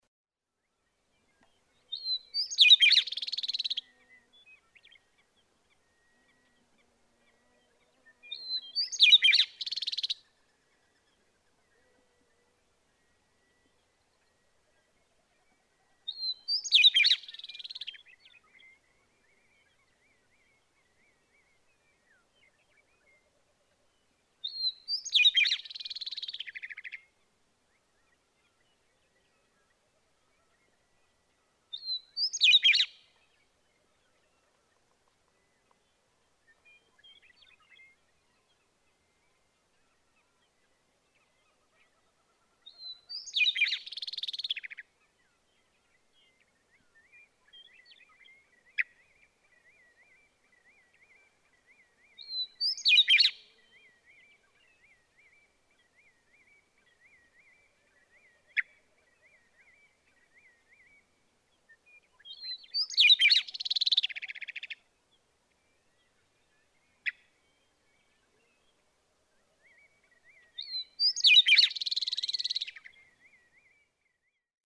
Western meadowlark
Routine singing involves repeating one of his ten or so song types several times; hear the female rattle in response to some of his songs.
Malheur National Wildlife Refuge, Burns, Oregon.
550_Western_Meadowlark.mp3